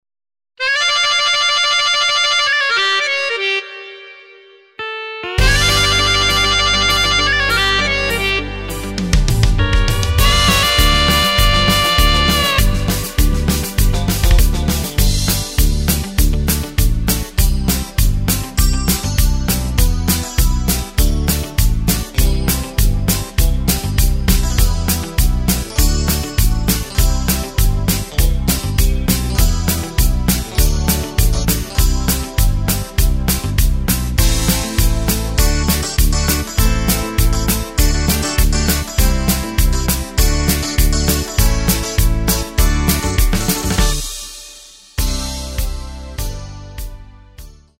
Takt:          2/4
Tempo:         100.00
Tonart:            G
Country aus dem Jahr 2025!
Playback mp3 Demo